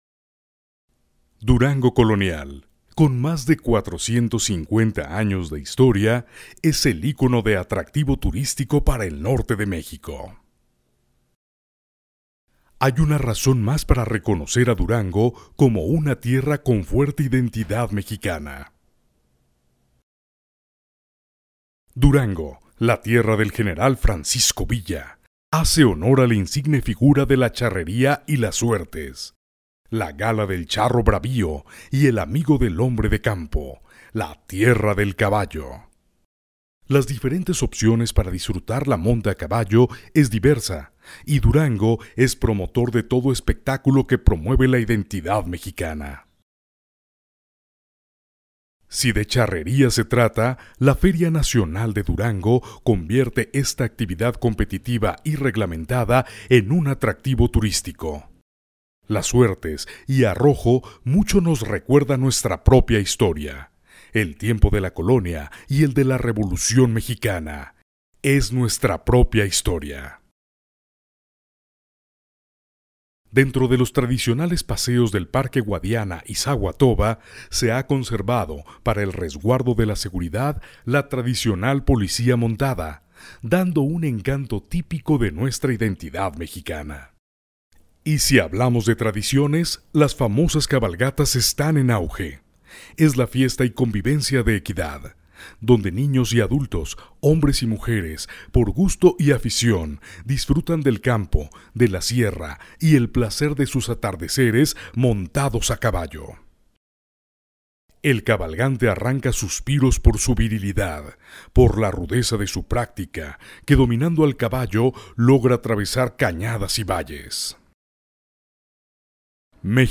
locutor versatil tanto para voz comercial, institucional, y doblaje
locutor profesional perfecto español
kastilisch
Sprechprobe: eLearning (Muttersprache):